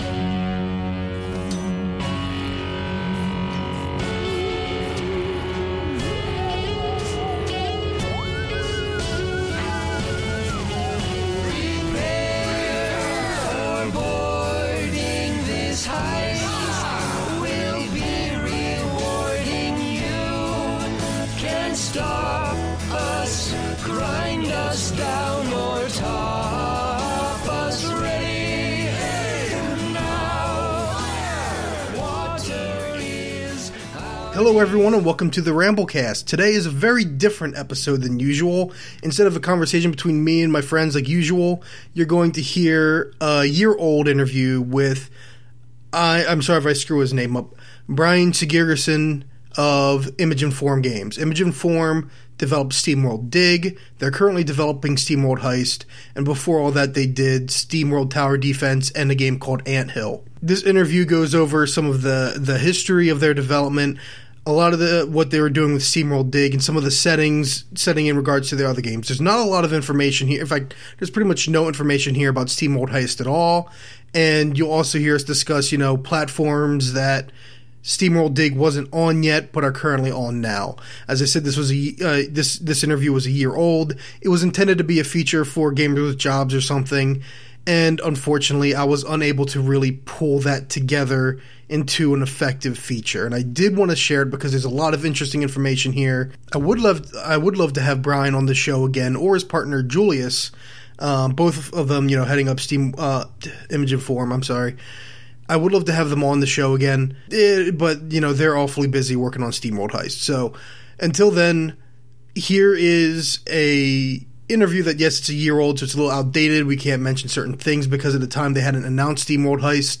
RambleCast - Interview with Image & Form